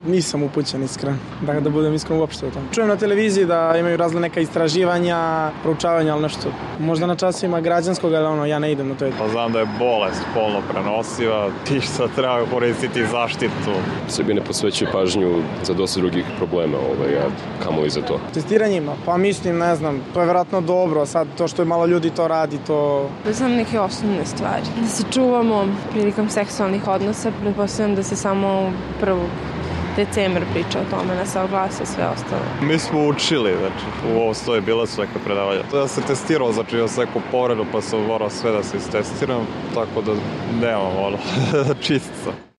Neki od mladih Beograđana rekli su za RSE da su o HIV-u najviše informisani preko medija.